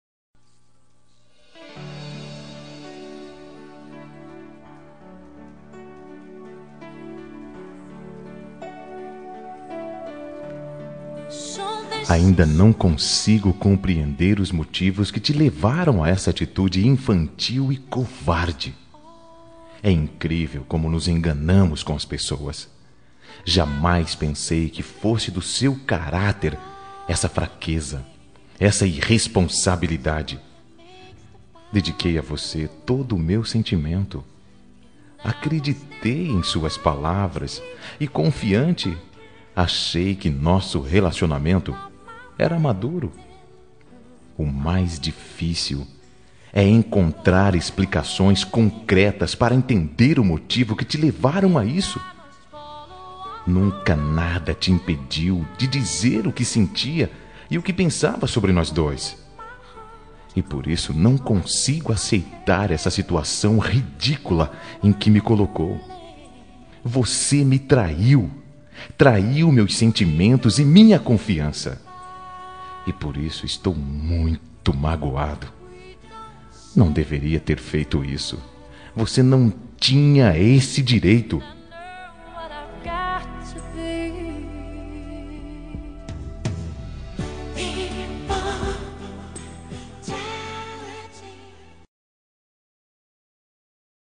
Toque para Não Terminar – Voz Masculina – Cód: 505 – Você me Traiu